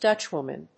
アクセント・音節Dútch・wòman